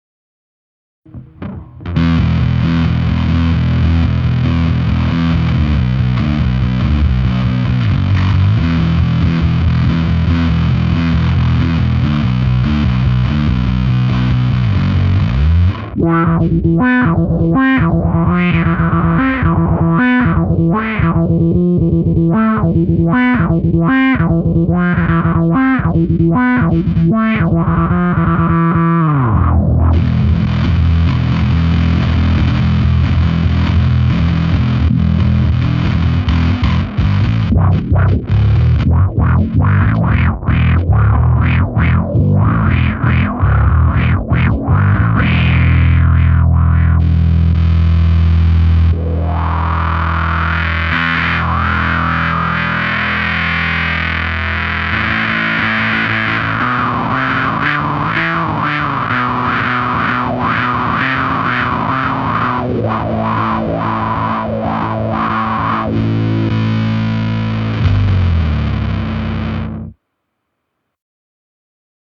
V.a die Transition vom Wah zum Fuzz klingt super unrund.
Und selbst bei sofort ist ein hörbarer Versatz da.
Effektpedal für E-Bass